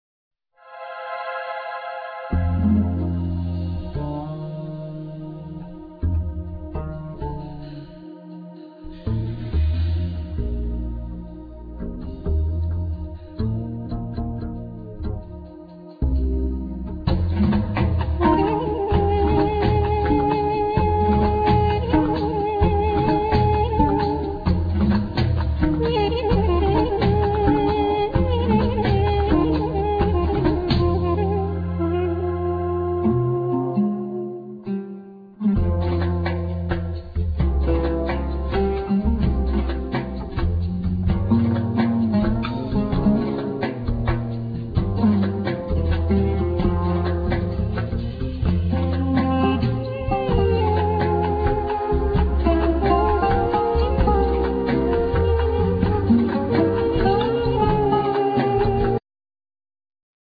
Kanun
Rek,Duf,Zilia,Durbakkeh
Double Bass
Ney
Oud,Vocals,Percussions,Keyboards